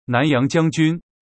ピンインfēng xìnnán yáng jiāng jūn
*2 音声は音読さんを利用